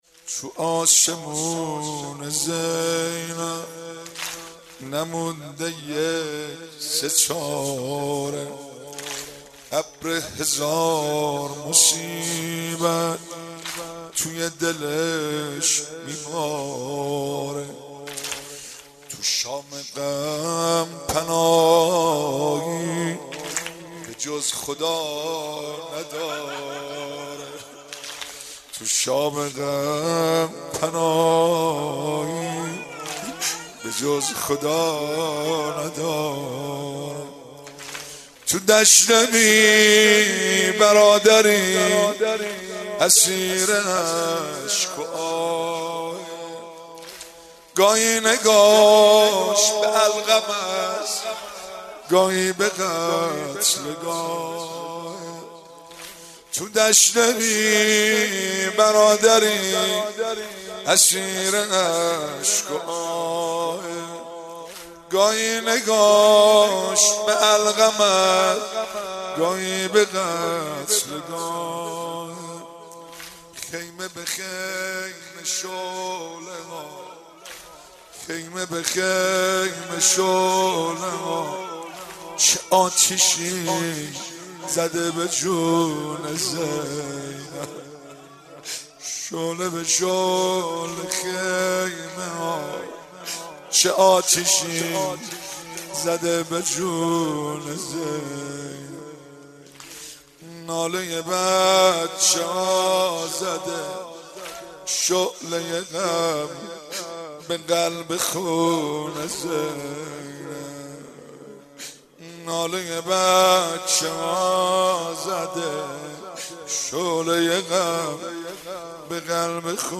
15 فروردین 97 - هیئت فاطمیون قم - واحد - تو آسمون زینب